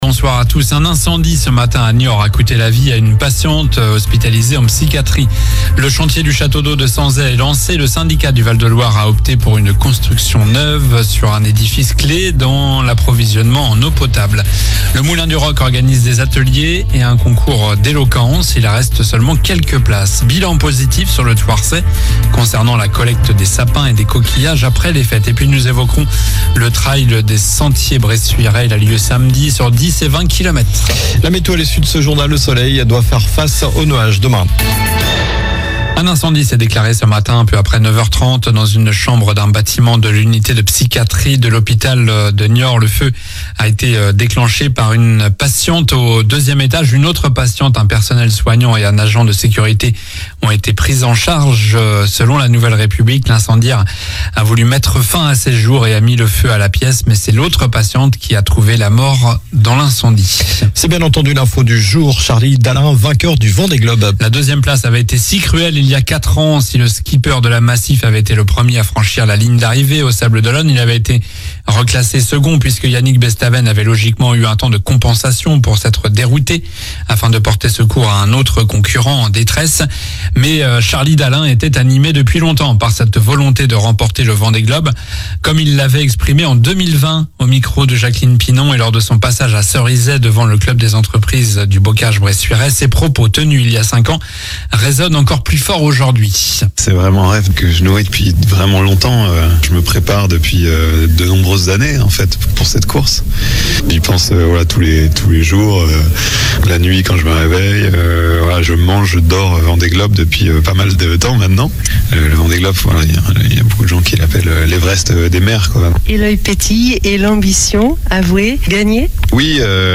Journal du mardi 14 janvier (soir)